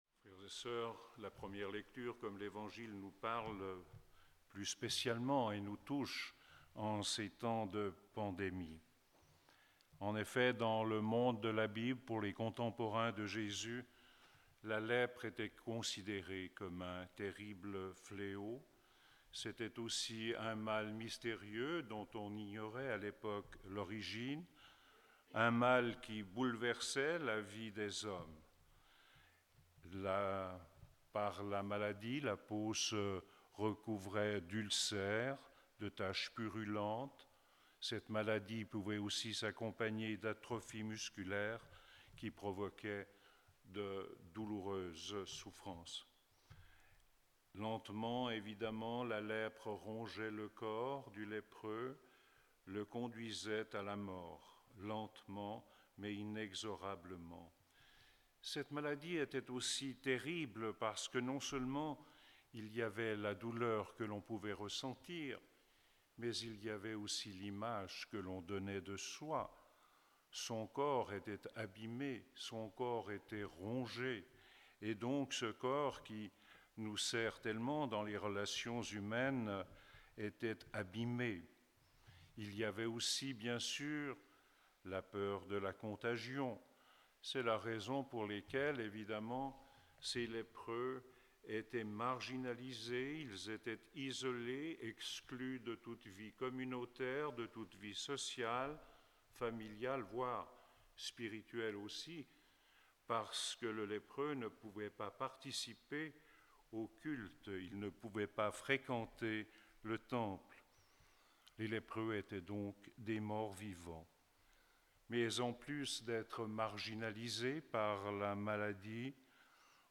Enregistrement en direct : l'homélie